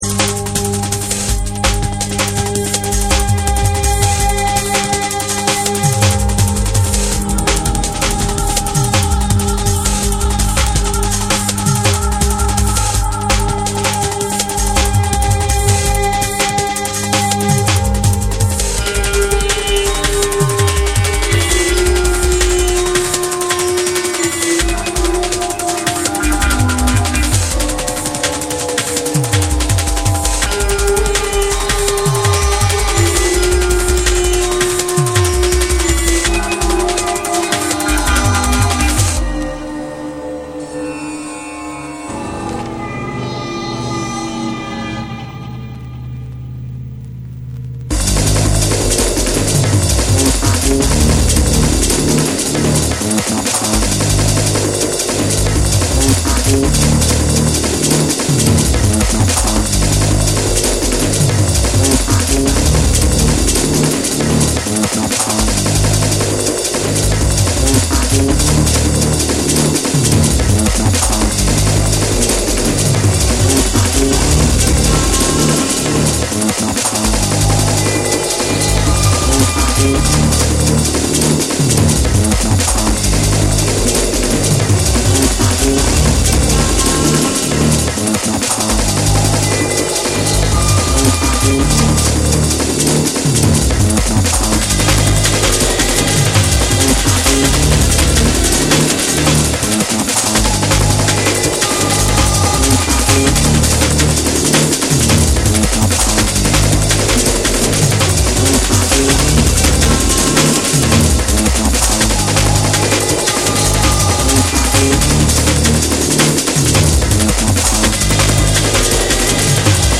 重厚なビートと細密なサウンド・デザインが炸裂。
BREAKBEATS